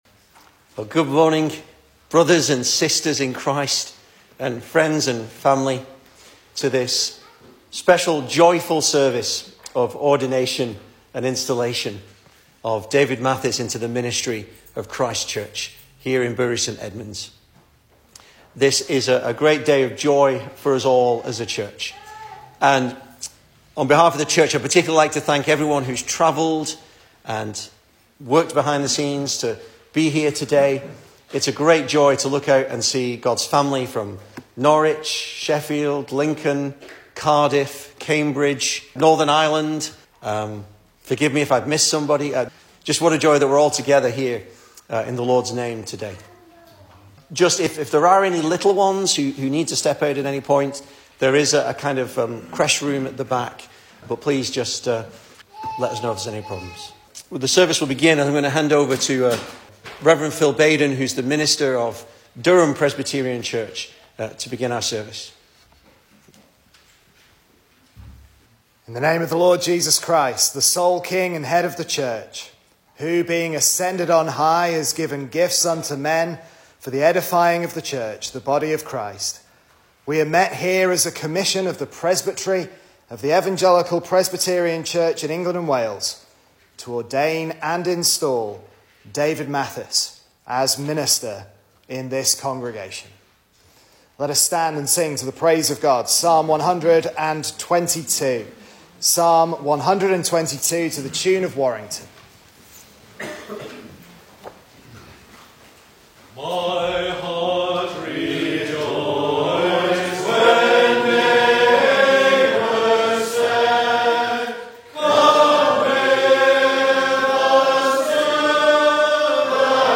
Full Service
Service Type: Special Service